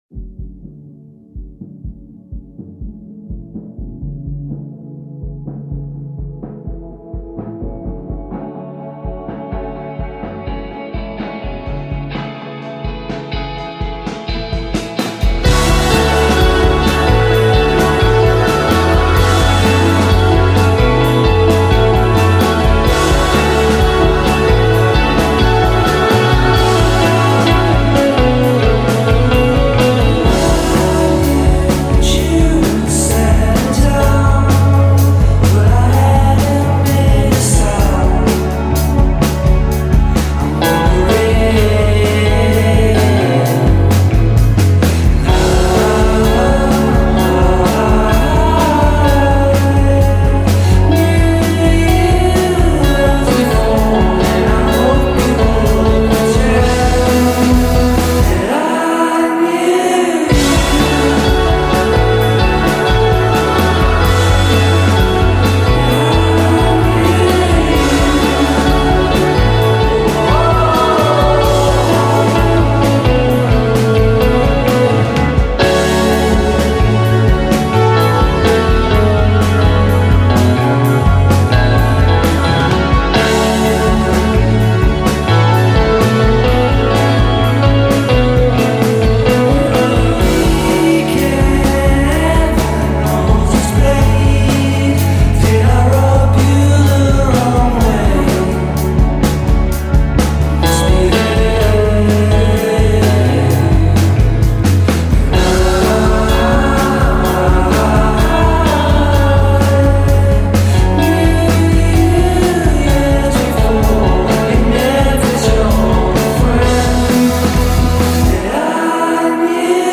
dream pop
Recorded in their hometown